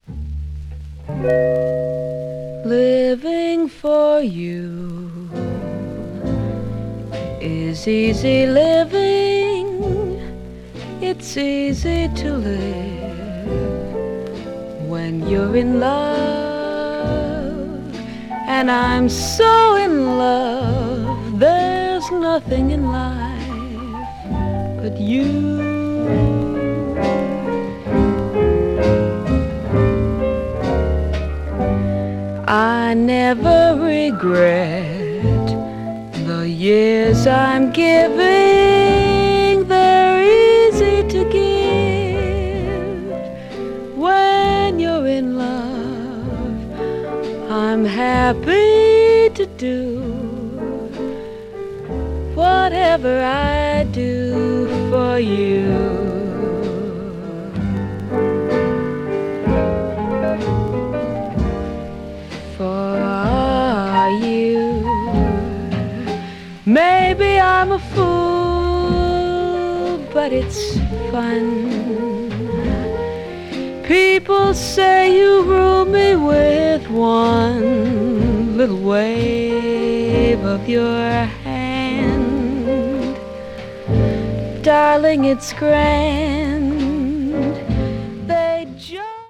media : EX/EX(some slightly noises.)
American jazz singer
drums
piano
guitar
bass
expressive and sultry vocals
jazz standard   jazz vocal   modern jazz   west coast jazz